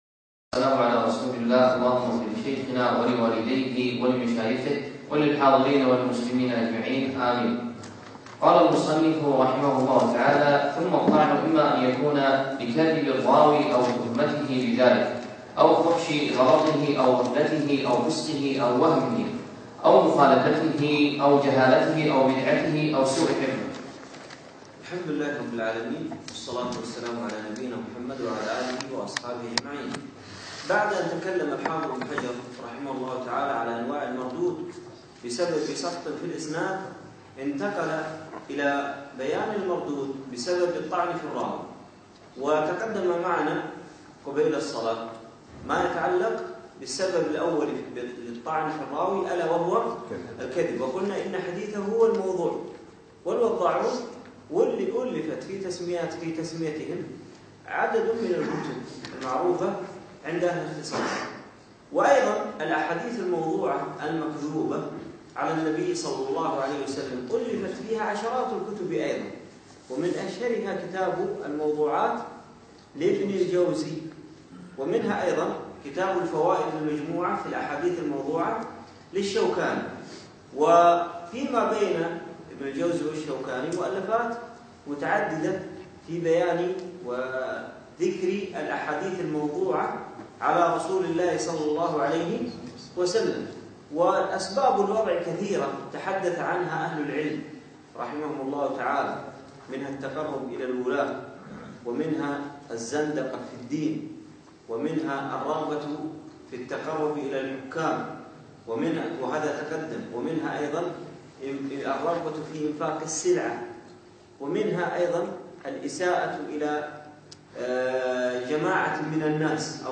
يوم الجمعة 6 جمادى أول 1438 الموافق 3 2 2017 في مسجد زين العابدين سعد ال عبدالله
الدرس الخامس